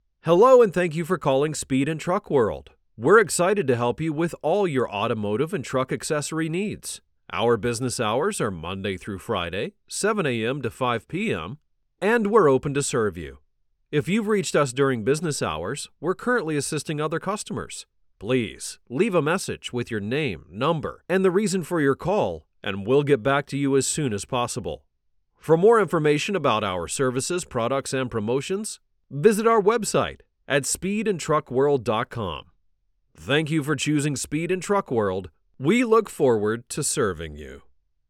English (American)
Deep, Natural, Friendly, Warm, Corporate
Telephony